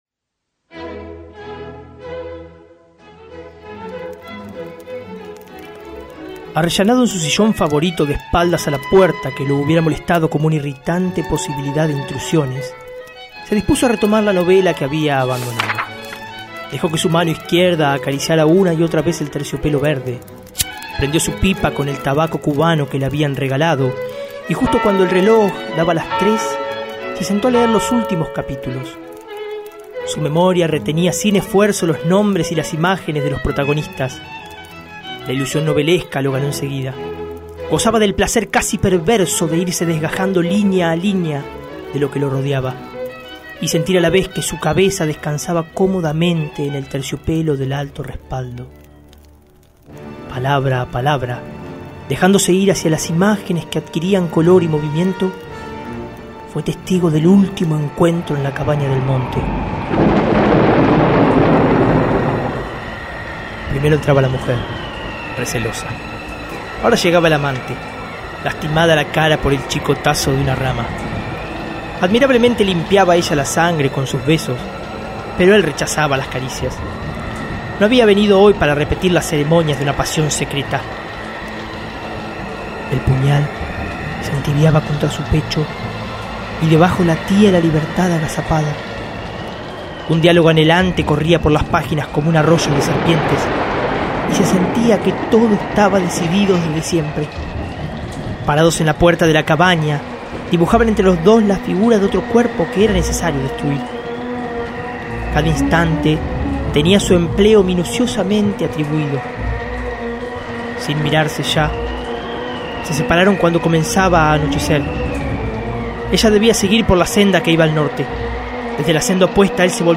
Título: Continuidad de los parques. Género: Cuento sonoro. Sinopsis: Un lector se introduce en las páginas de una novela, como si su propia realidad se esfumara para vivir dentro de una fantasía.